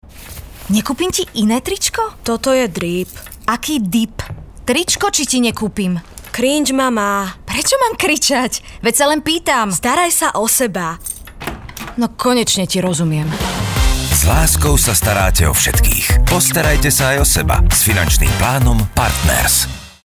Rozhlasový spot dievča